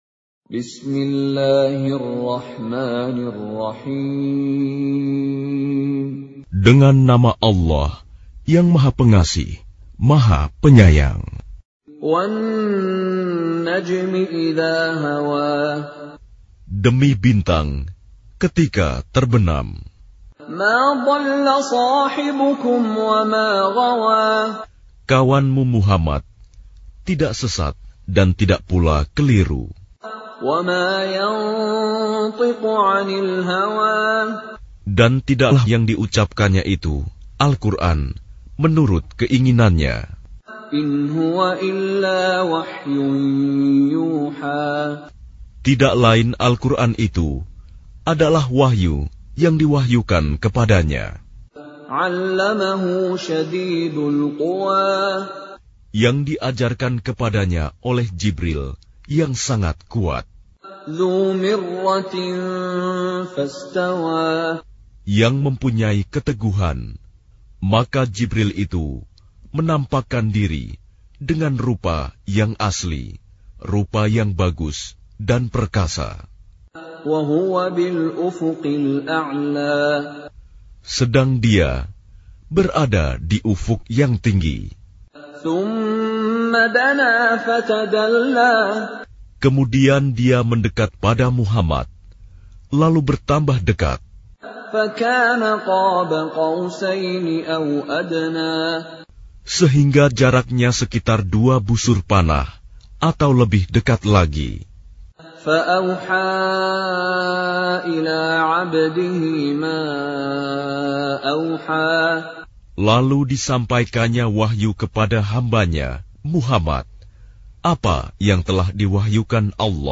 Surah Sequence تتابع السورة Download Surah حمّل السورة Reciting Mutarjamah Translation Audio for 53. Surah An-Najm سورة النجم N.B *Surah Includes Al-Basmalah Reciters Sequents تتابع التلاوات Reciters Repeats تكرار التلاوات